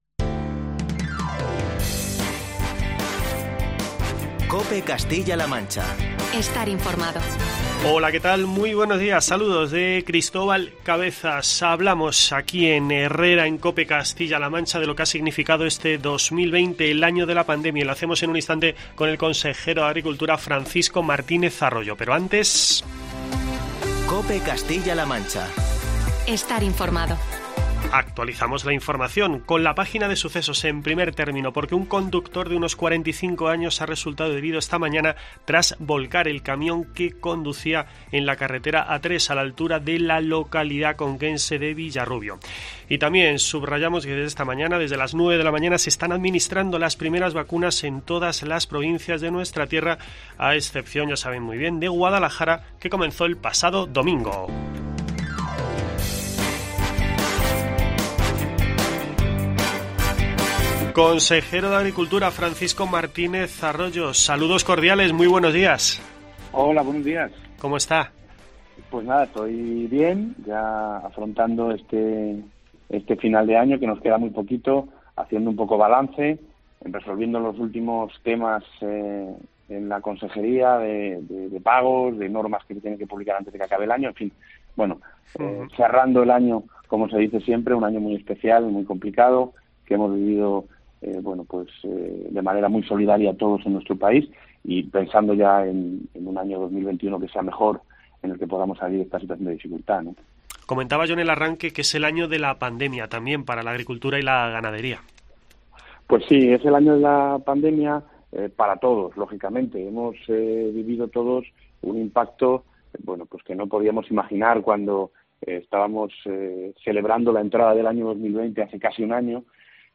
Entrevista con Francisco Martínez Arroyo, consejero de Agricultura